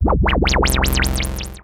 Unused sound clip from Luigi's Mansion
LM_Early_Warbling.oga.mp3